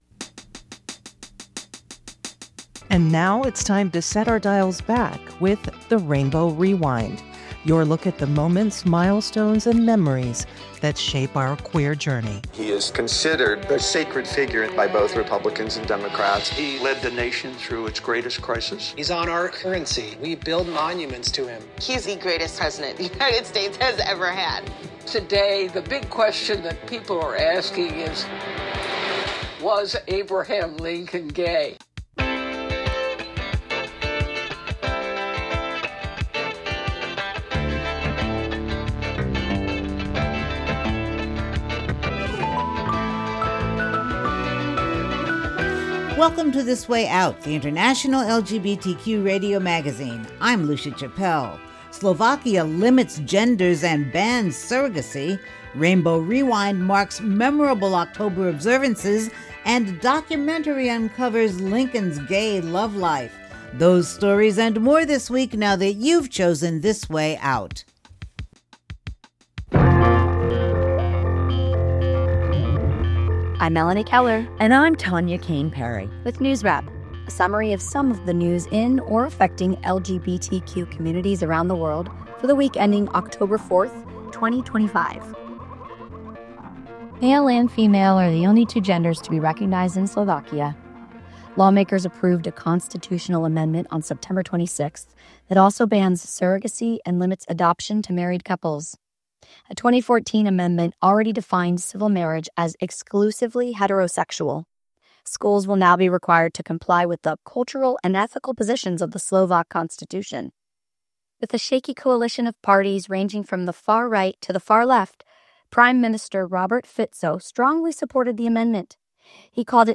The International LGBTQ radio magazine wk of 10-06-25 Program Type